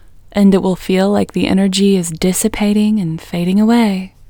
IN – the Second Way – English Female 18